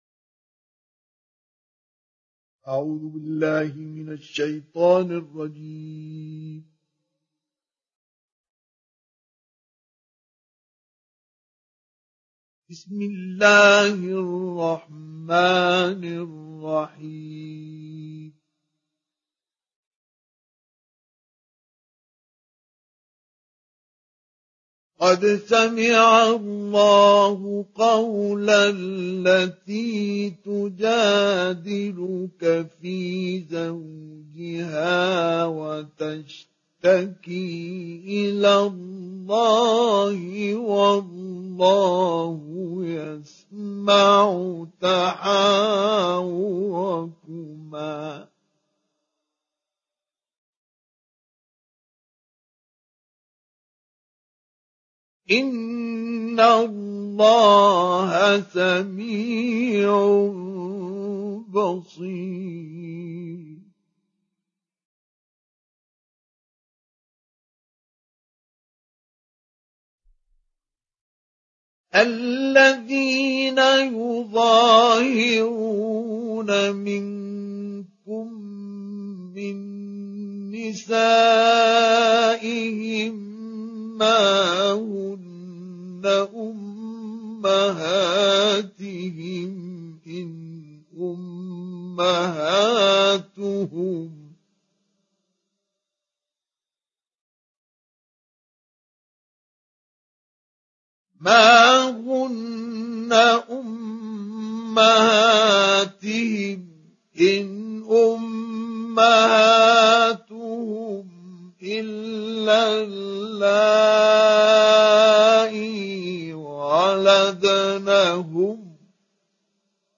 Download Surah Al Mujadilah Mustafa Ismail Mujawwad